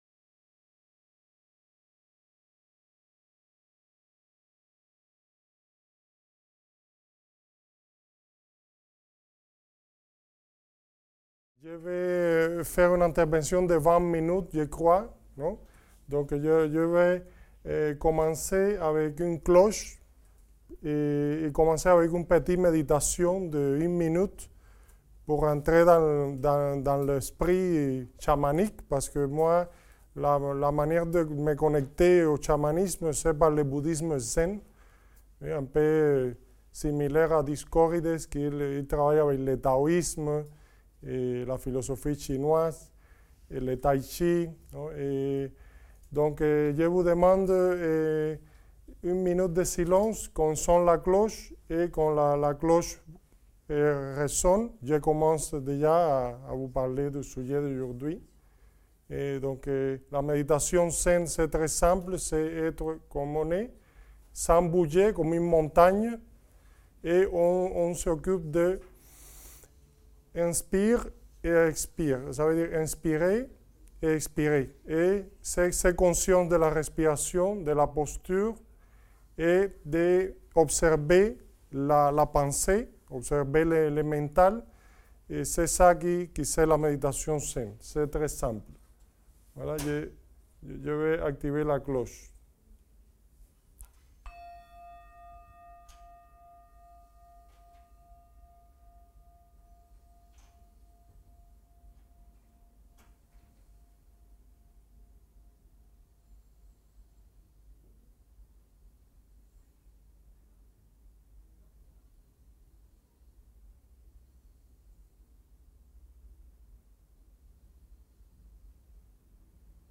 Les Hommes Assis - Dialogues d'Amazonie Dans le cadre de la manifestation Les Hommes Assis - Dialogues d'Amazonie organisée par Symphonies Culturelles, la Fondation accueille, le mercredi 15 novembre, le colloque universitaire Chamanisme et art rupestre amazonien.